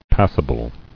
[pas·si·ble]